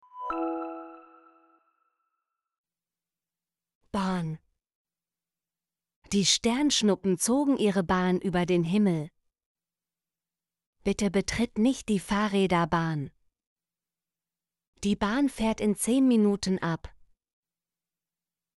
bahn - Example Sentences & Pronunciation, German Frequency List